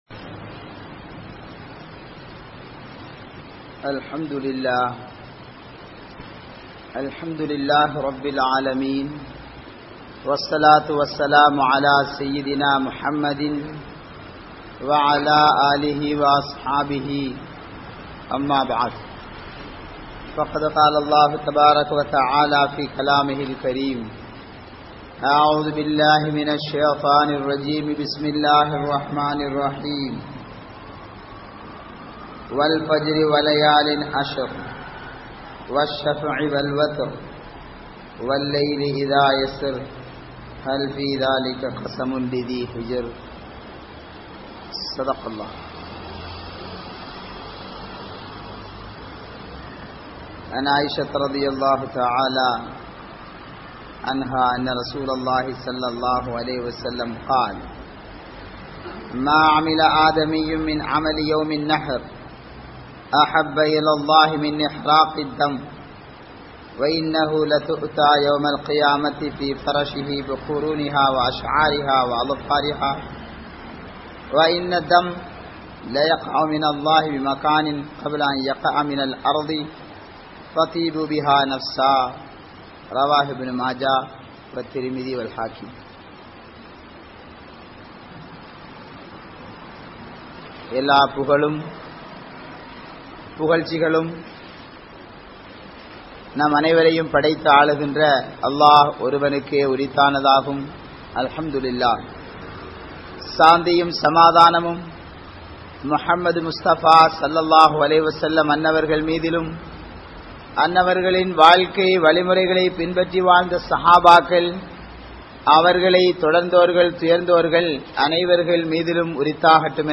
Ulhiya (உழ்ஹிய்யா) | Audio Bayans | All Ceylon Muslim Youth Community | Addalaichenai